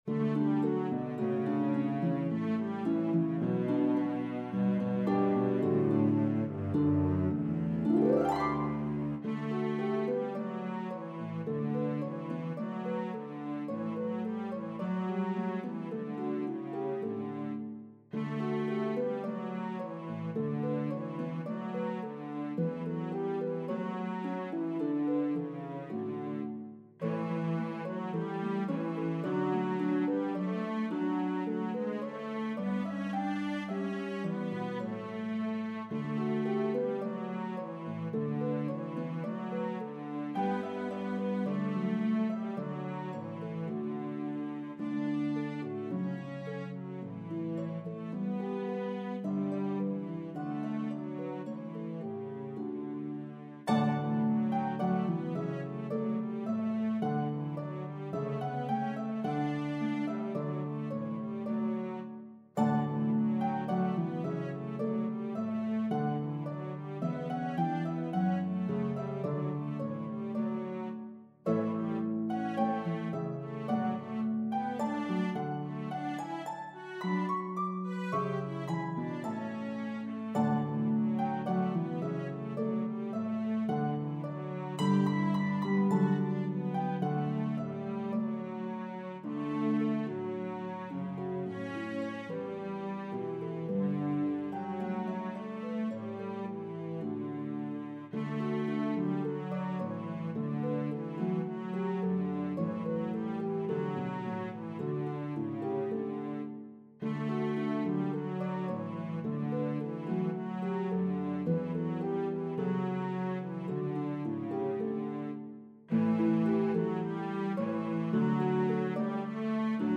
as a duet for lever harp
Harp and Cello version